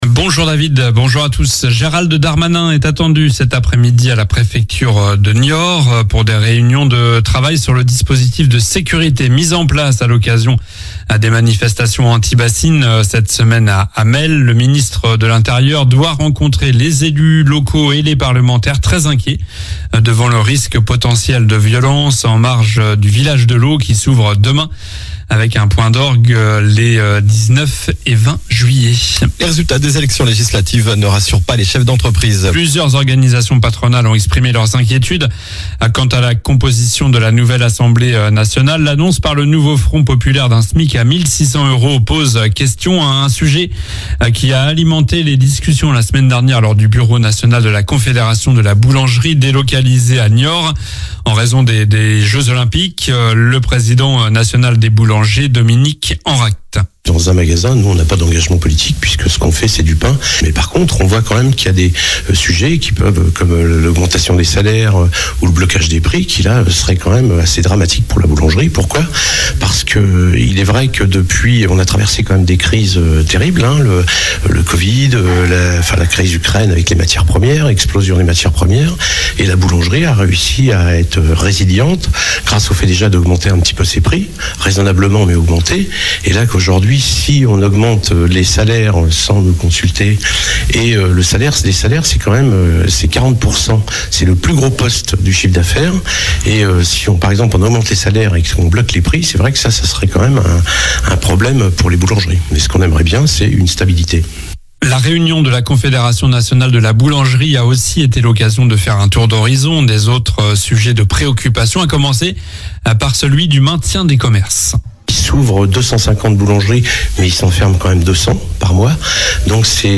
Journal du lundi 15 juillet (matin)